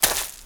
STEPS Leaves, Walk 07.wav